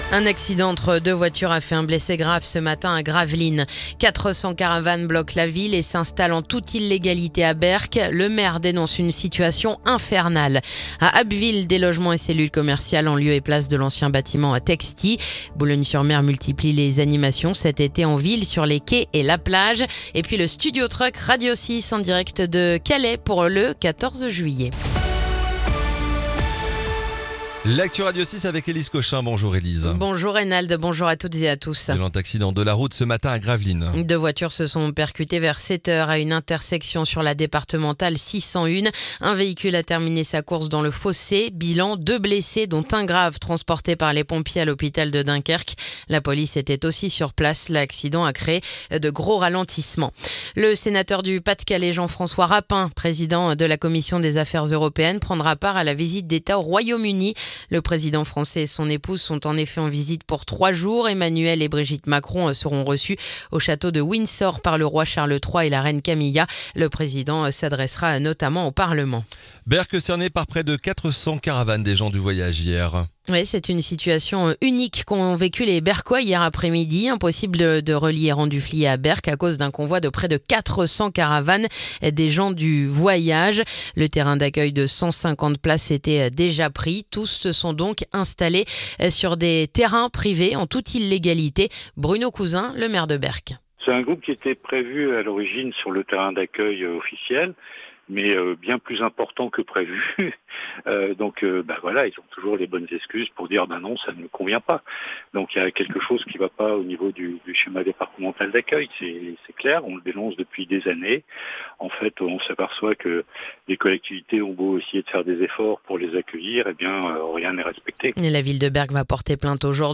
Le journal du mardi 8 juillet